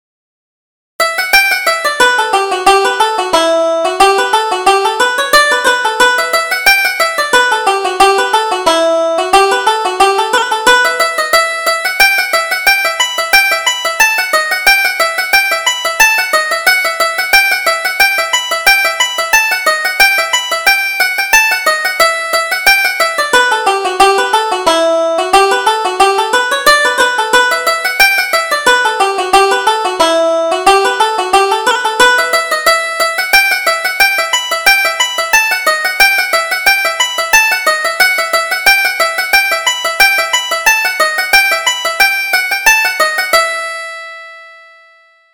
Reel: The Reel of Mullinivat